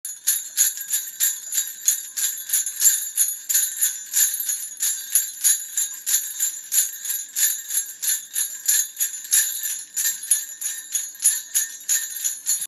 Santa Christmas Bells Sound Effect Free Download
Santa Christmas Bells